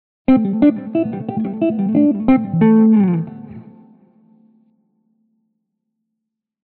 HYBRID PICKING STUDIAMO QUALCHE LICK